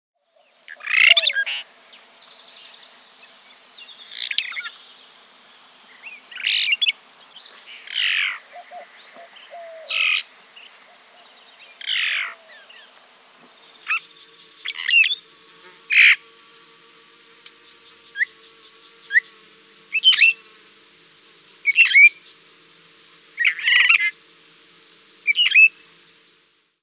Bird Sound
Call a rising "wurp." Song complex, with at least 14 different identifiable elements, though notes and phrases often seem weak and disjointed. Includes a sprightly, whistled "wheeda-lay"
Phainopepla.mp3